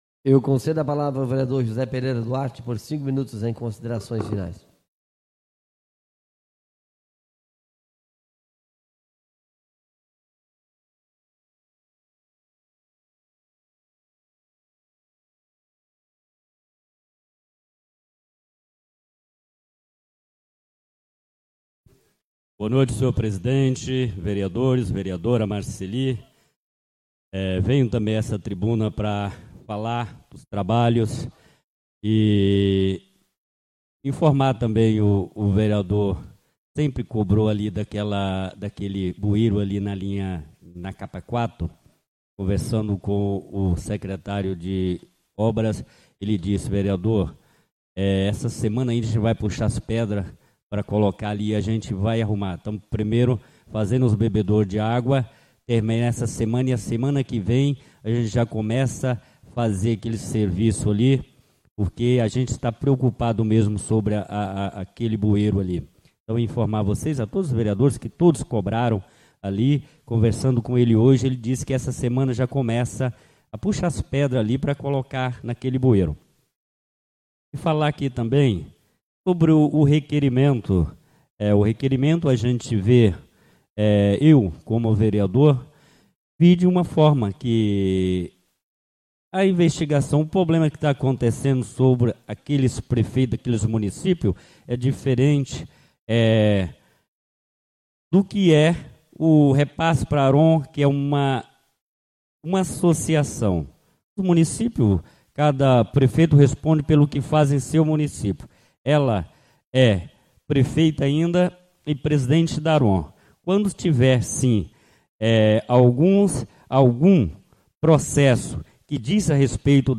Oradores do Expediente (29ª Ordinária da 4ª Sessão Legislativa da 6ª Legislatura)